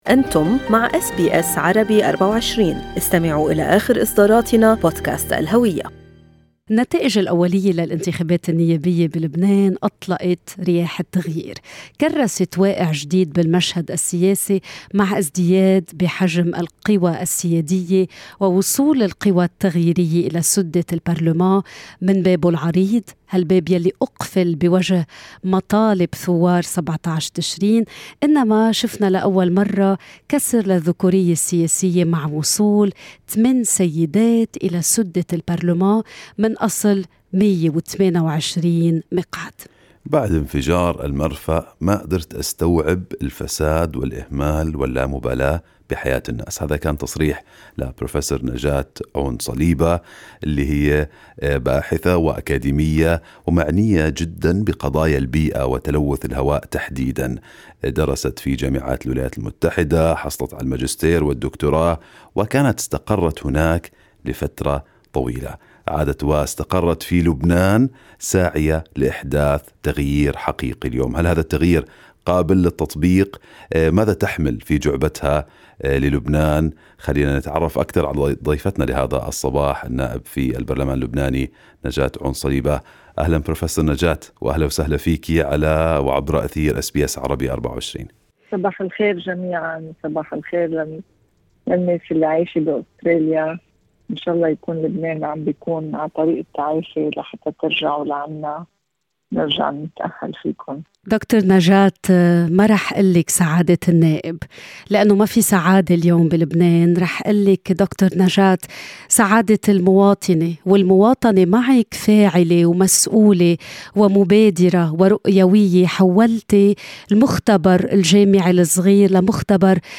"كل شيء يرخص أمام الوطن": النائب اللبنانية نجاة عون صليبا تتحدث لأس بي أس عربي24 عن رحتلها من المختبر إلى البرلمان